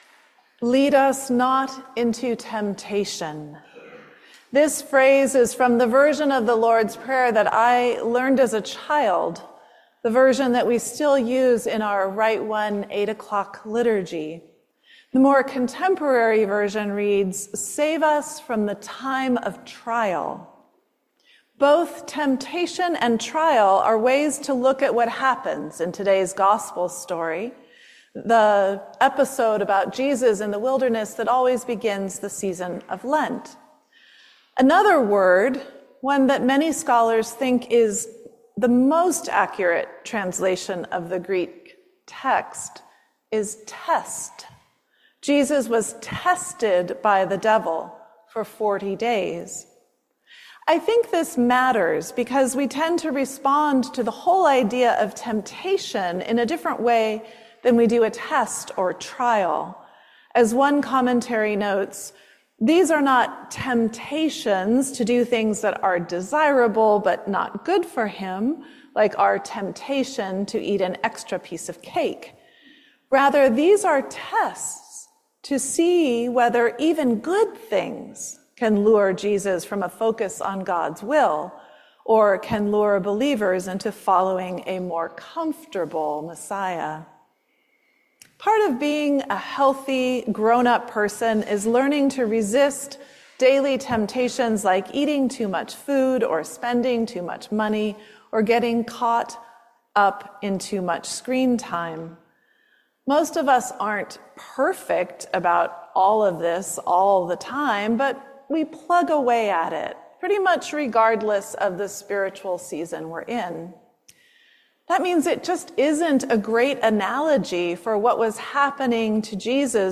Sermon on March 9, 2025“Being Tested in the Wilderness”By